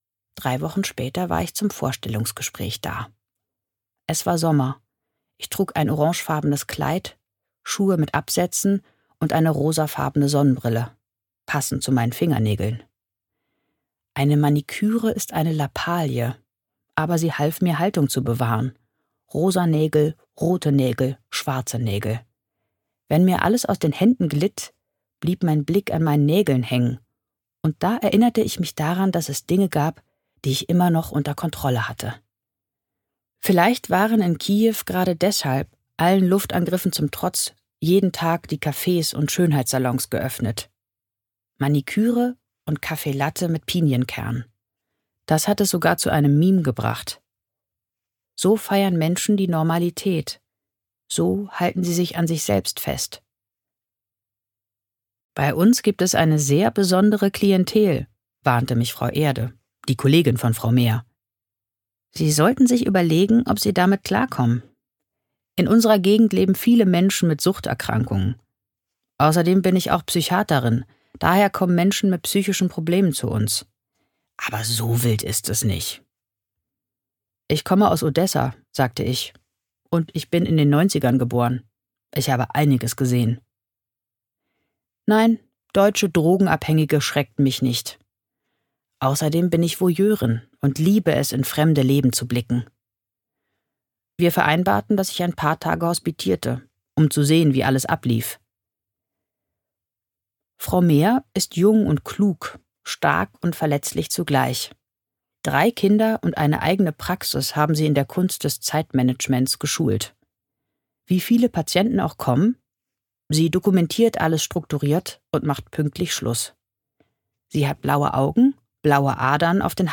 Ein Hörbuch, das Mut macht: vom Leben einer jungen ukrainischen Ärztin und dem Versuch, Trost in alltäglichen Dingen zu finden.